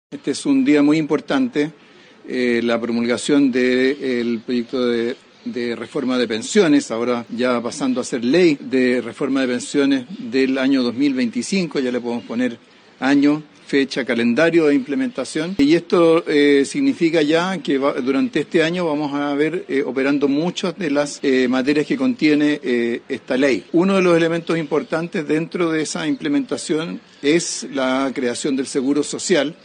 En tanto, el ministro de Hacienda, Mario Marcel, señaló que este año se verán operando diversas medidas contenidas en la reforma.